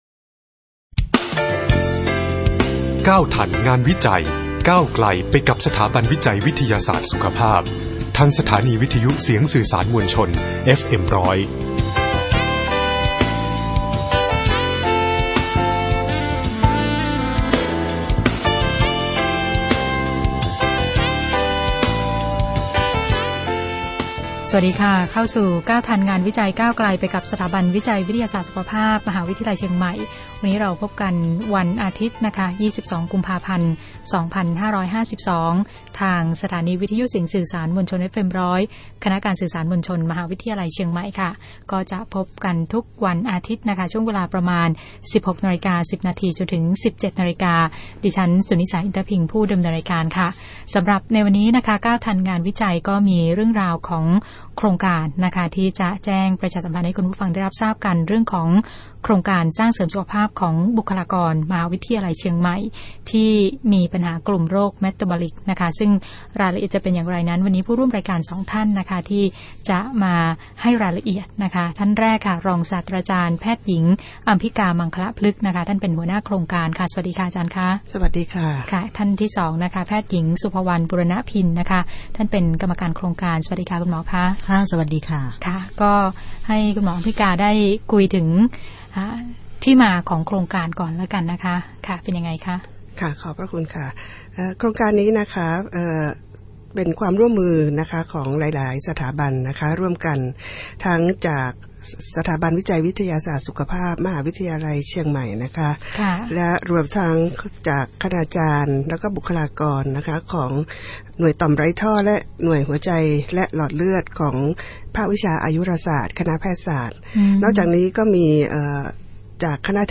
รายการวิทยุ | รายการโทรทัศน์, วิทยุ | Page 15